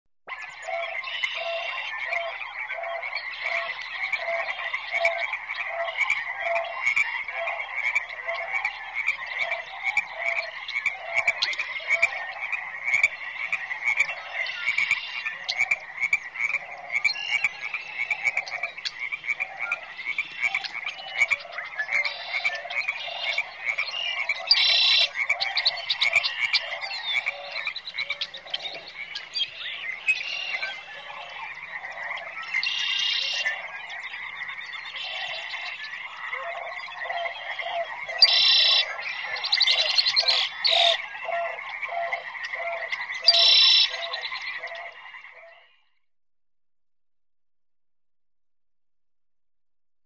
Так соскучились по звукам весны, что не удержалась, нашла сопровождение похожее на то, что там услышала...Точно, всю ночь были слышны их трели. После разлива территория водная получилась очень большая, звучание было таким массивным и уходило далеко, что было похожим на сплошное непереставаемое эхо хора лягушек, сверчков, птиц...